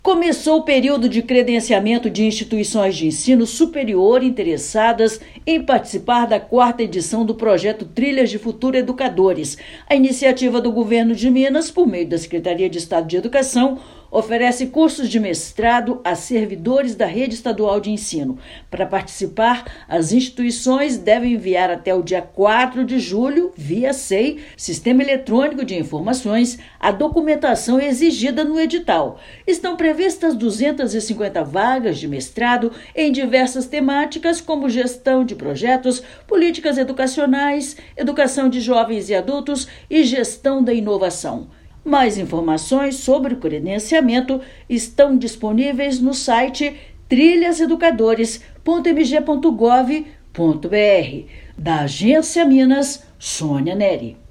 [RÁDIO] Começou o credenciamento de Instituições de Ensino Superior para o Trilhas de Futuro Educadores
Instituições interessadas têm até 4/7 para enviar a documentação exigida no edital e participar do processo de credenciamento. Ouça matéria de rádio.